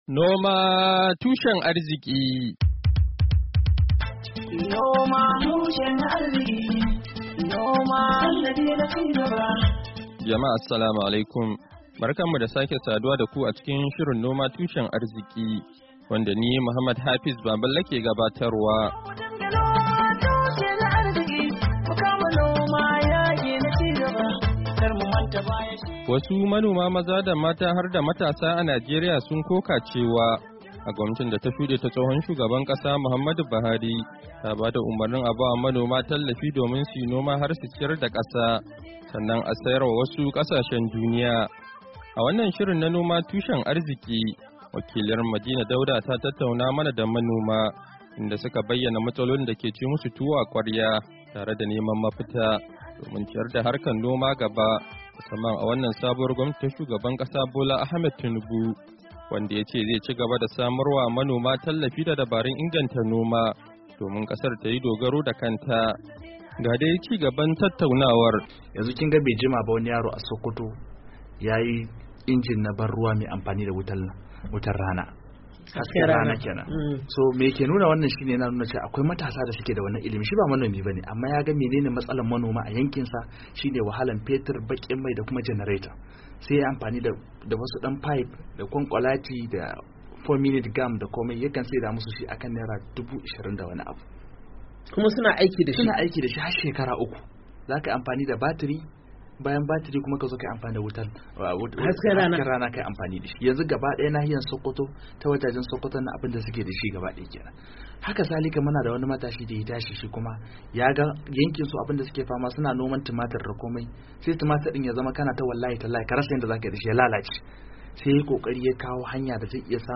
Shirin na wannan makon, zai kawo muku kashi na hudu na ci gaba da tattaunawa da shugabannin kungiyoyin manoma mata da maza a Najeriya wanda suka koka cewa tsohuwar gwamnatin Buhari ta ba da umarnin a basu tallafi domin su yi noma har su ciyar da kasa sannan a sayar wa wasu kasashen duniya, amma tallafin bai kai gare su ba.